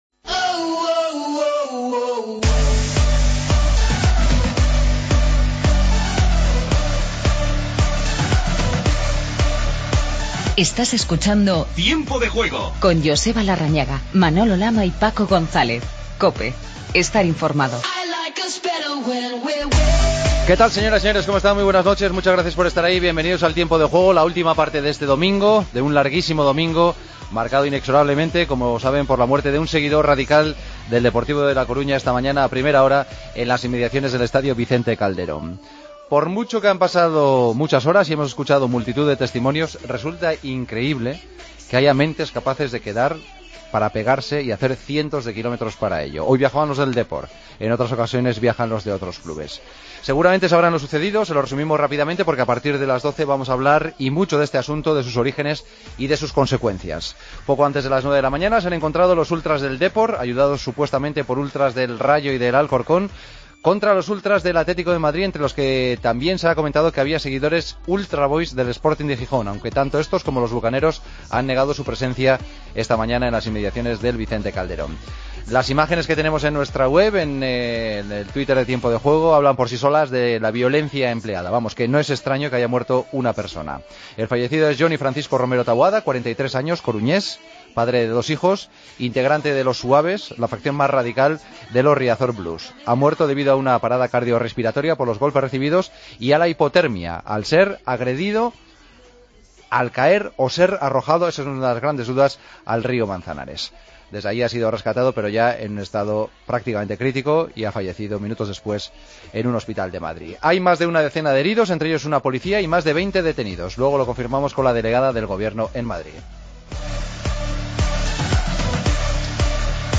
El Barcelona gana 0-1 al Valencia con un gol de Busquets en el minuto 93. Escuchamos a Busquets y Luis Suárez y entrevista a Diego Alves.